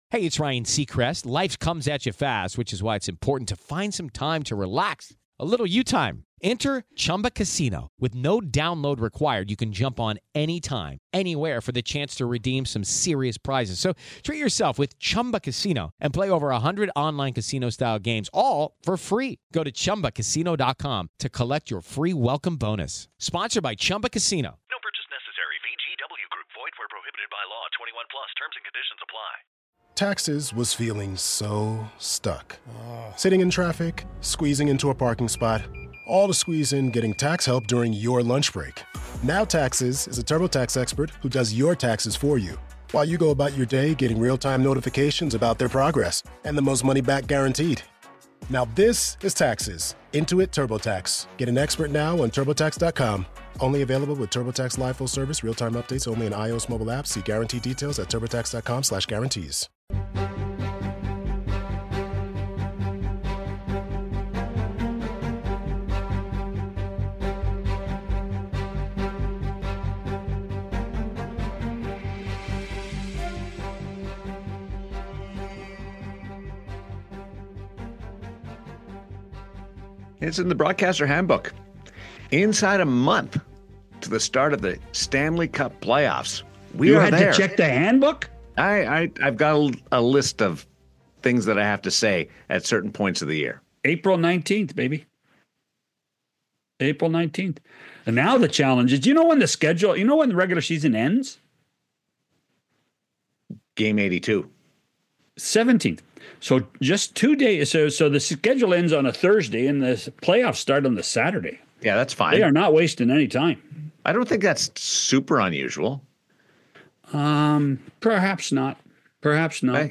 Join us for an exciting conversation with Luc Robitaille, a Hall of Famer and the President of Business Operations for the Los Angeles Kings, who spent an impressive 14 seasons as a player with the team.